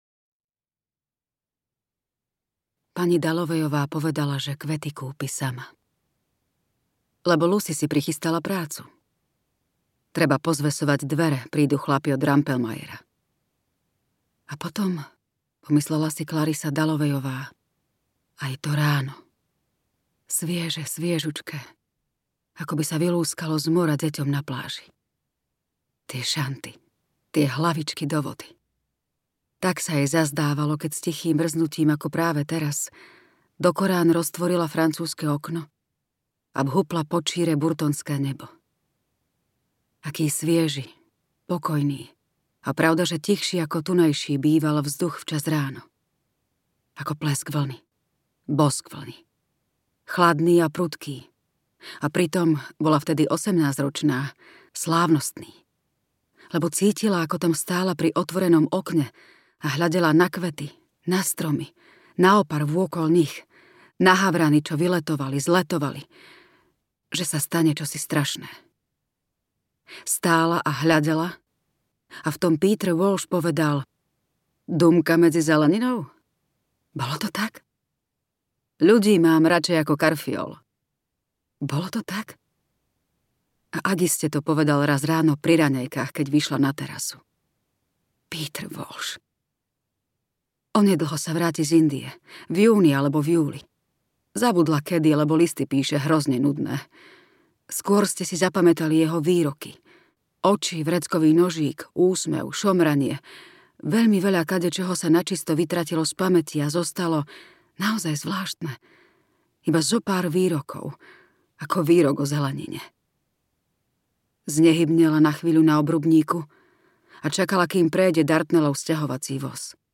Pani Dallowayová audiokniha
Ukázka z knihy